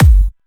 VDE 128BPM Silver Kick.wav